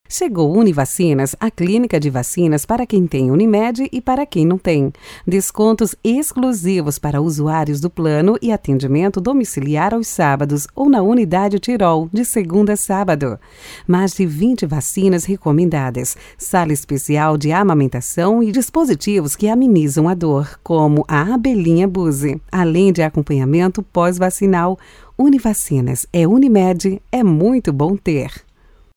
LOCUÇÃO PADRAO: